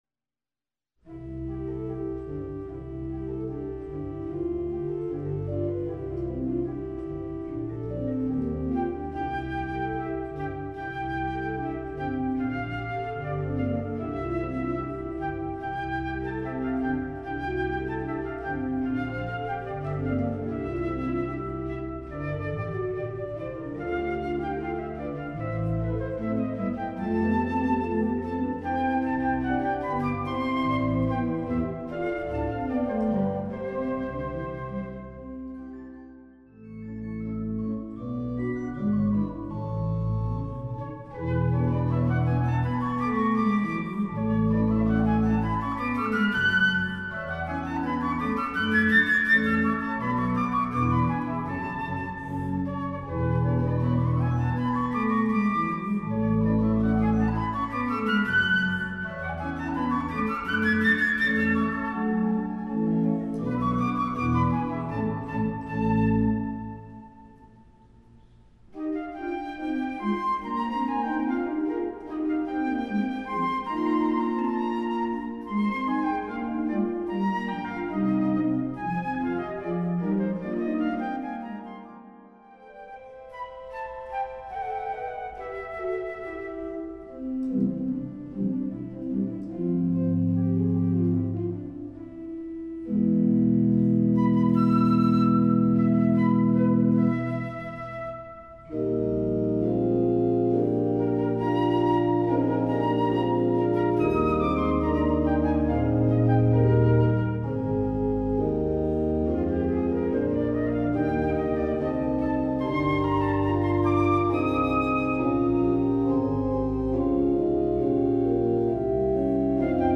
Voicing: Flute and Organ